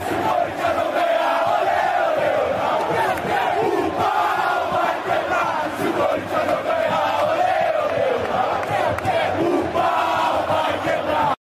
se o corinthians nao ganhar ole ole ola Meme Sound Effect
This sound is perfect for adding humor, surprise, or dramatic timing to your content.